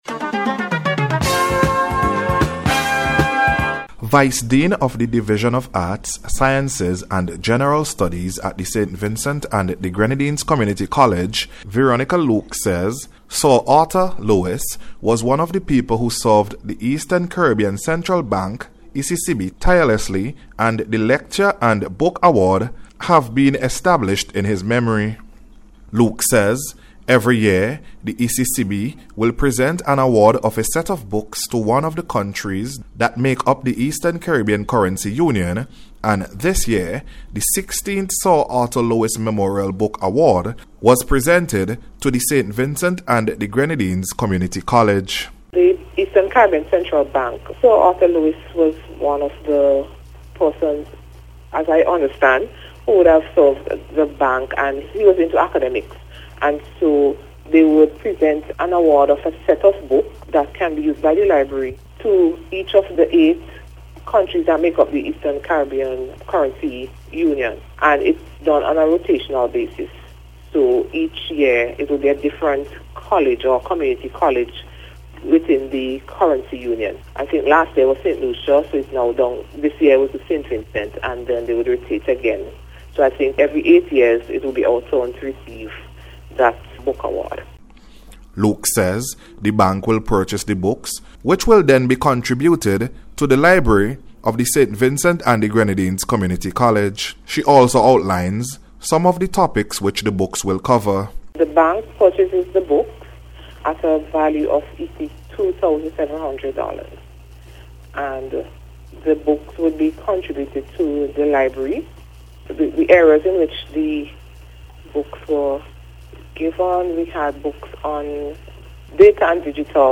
NBC’s Special Report- Wednesday 20th November,2024
COMMUNITY-COLLEGE-BOOK-AWARD-REPORT.mp3